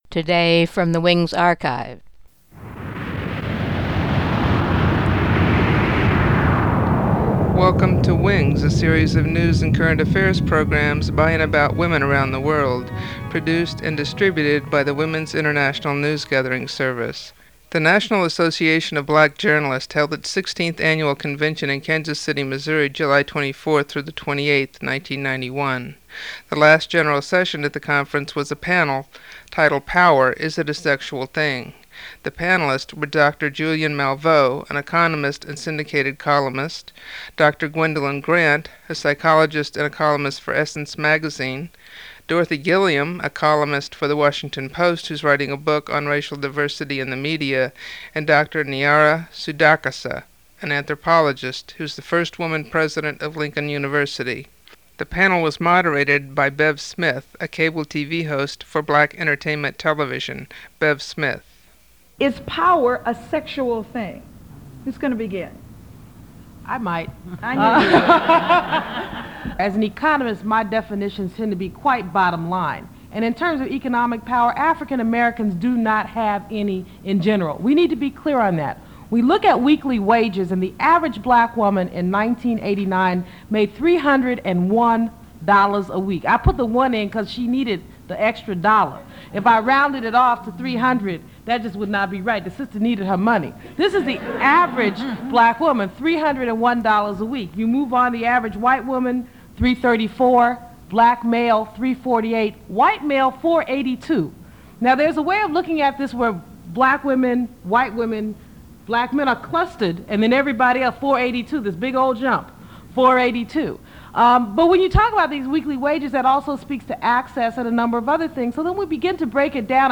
NABJ panel: Power, Is It a Sexual Thing?